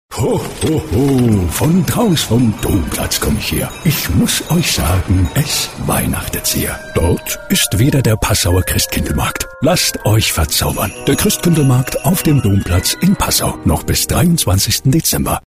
Deutsch Werbung Müllerbrei